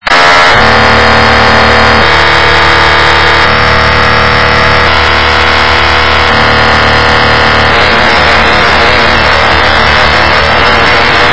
sstv.mp3